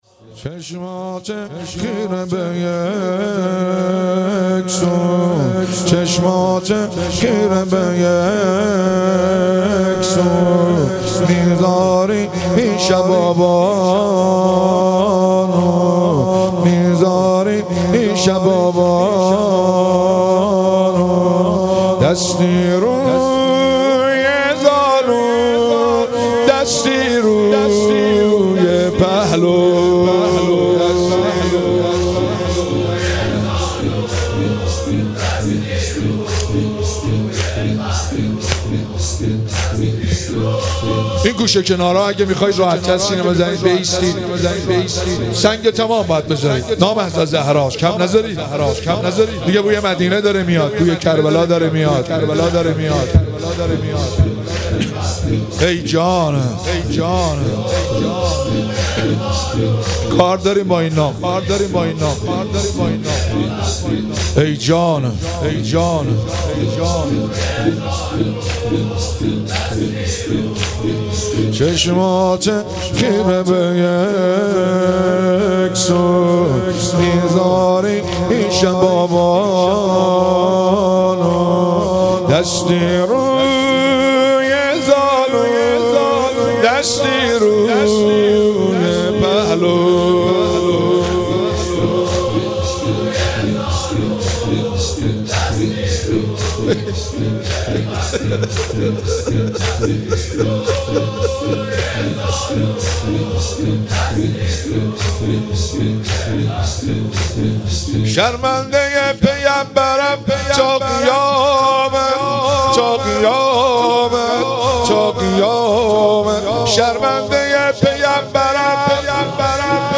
مراسم هفتگی25آذر
زمینه - چشماته خیره به یک سوء